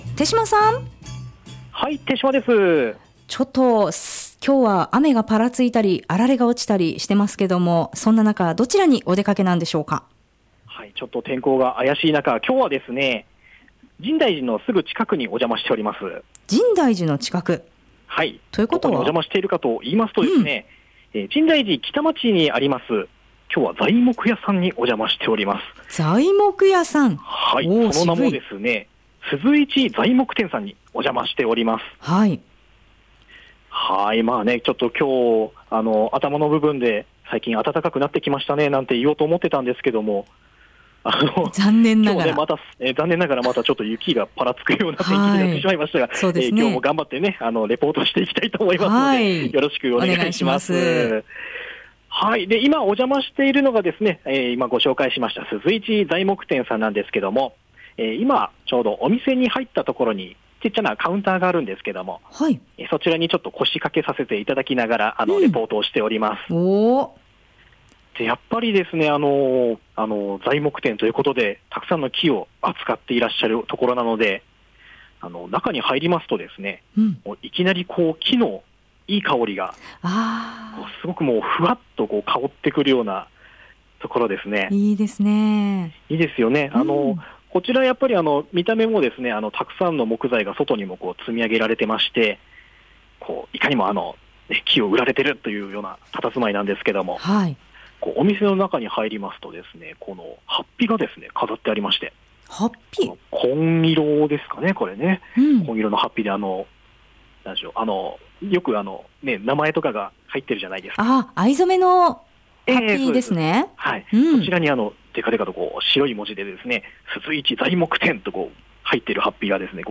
本日の放送音声はコチラ↓ 午後のカフェテラス 街角レポート 2018-02-22(木) 鈴一材木店 こちらの鈴一材木店さんには 約７０種類 の 木材 が取り揃えられていて、 一般のお客様はもちろんのこと、専門家や職人の方も遠くからお越しになります。